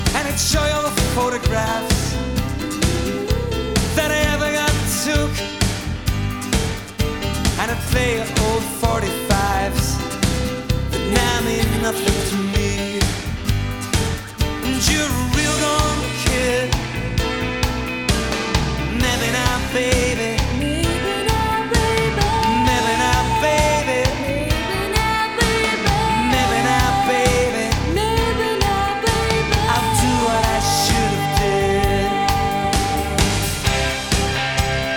Жанр: Поп музыка / Рок / Альтернатива / Джаз